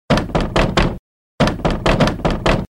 敲门.mp3